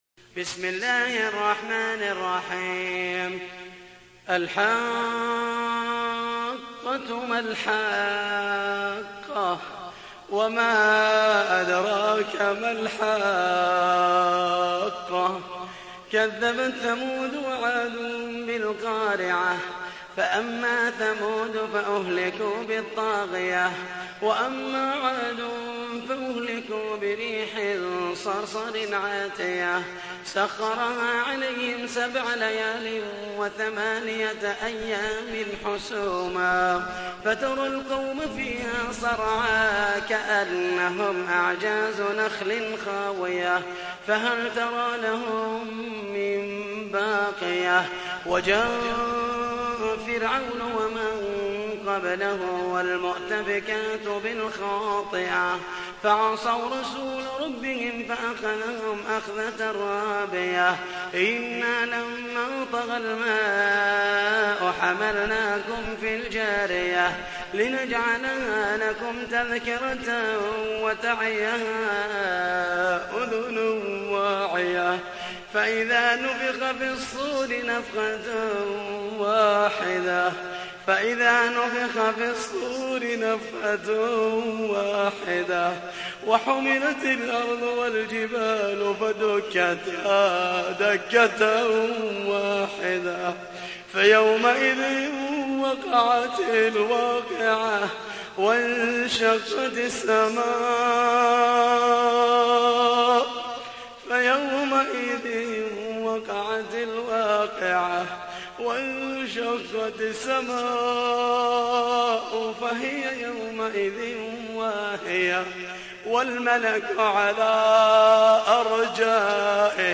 تحميل : 69. سورة الحاقة / القارئ محمد المحيسني / القرآن الكريم / موقع يا حسين